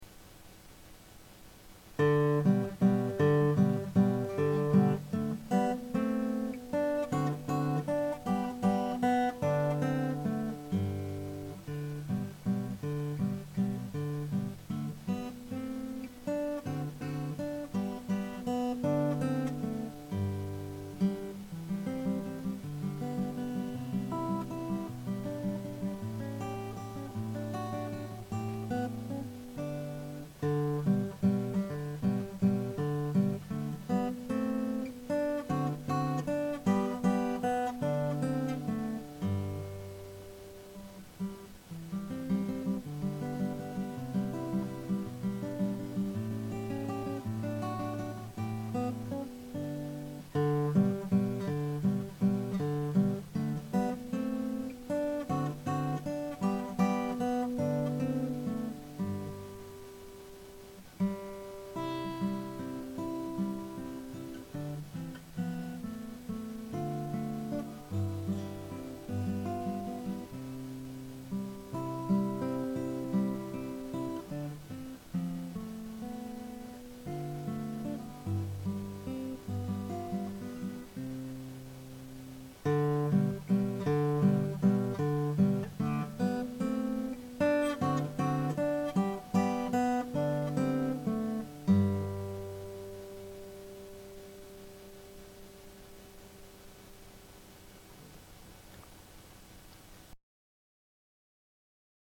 Classical Guitar Lessons in Wheaton